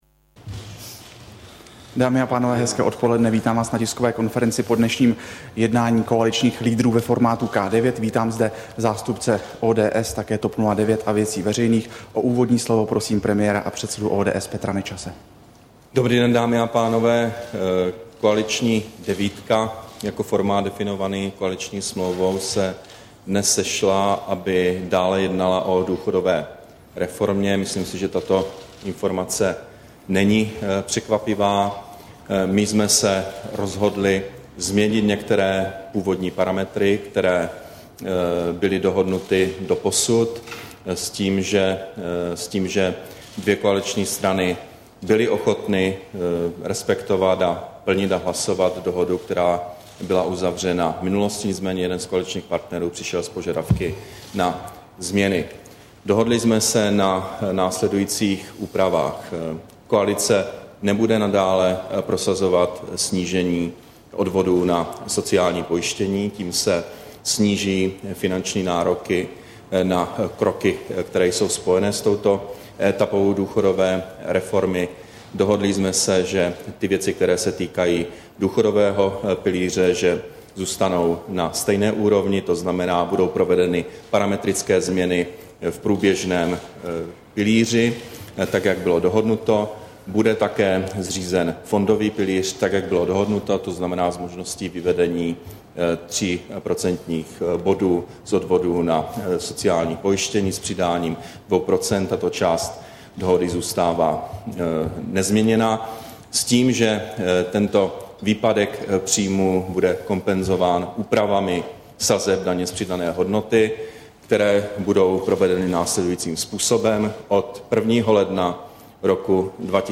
Brífink po jednání koaličních lídrů ve formátu K9, 10. března 2011